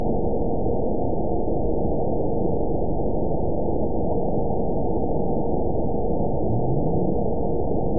event 920599 date 03/31/24 time 21:07:06 GMT (1 year, 8 months ago) score 9.53 location TSS-AB02 detected by nrw target species NRW annotations +NRW Spectrogram: Frequency (kHz) vs. Time (s) audio not available .wav